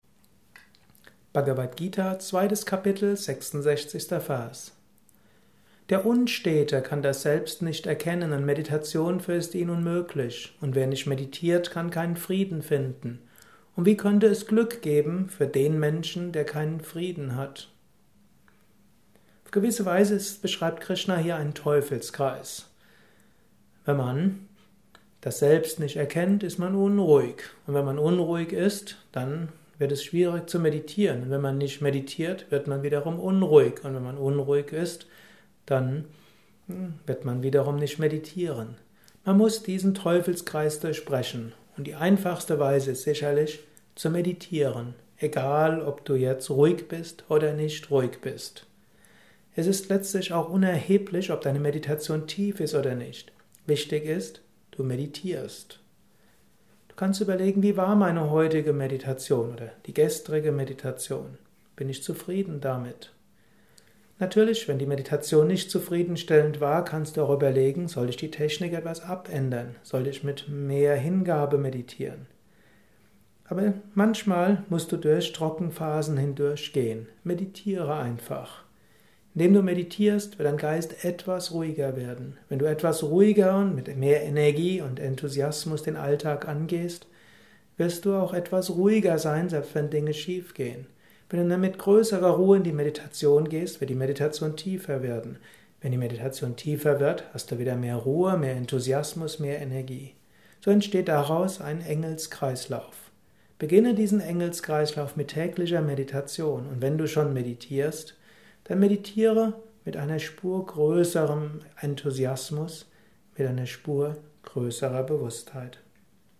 Kurzvortrag über die Bhagavad Gita